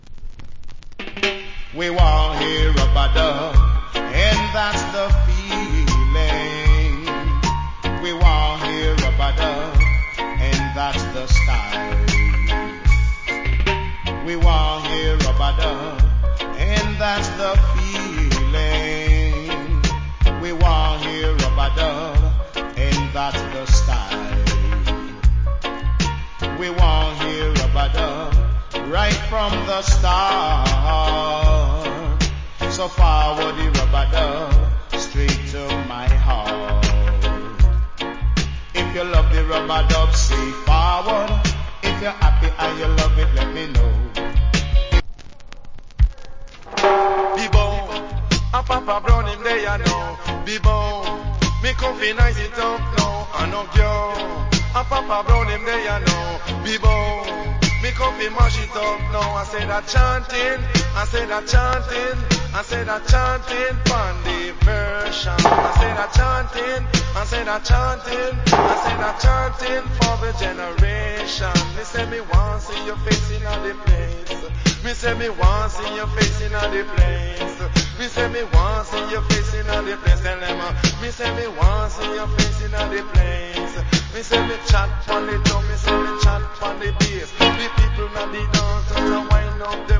80's. Wicked Dancehall Vocal. Nice Dub.